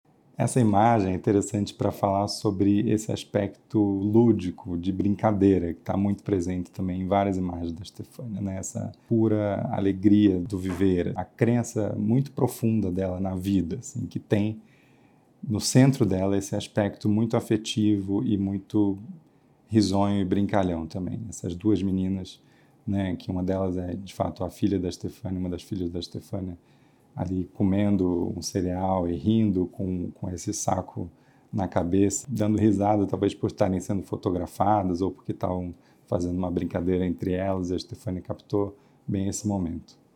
Faixa 2 - Comentário da curadoria